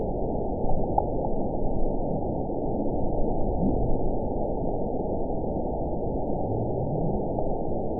event 917254 date 03/25/23 time 21:01:16 GMT (2 years, 1 month ago) score 9.51 location TSS-AB03 detected by nrw target species NRW annotations +NRW Spectrogram: Frequency (kHz) vs. Time (s) audio not available .wav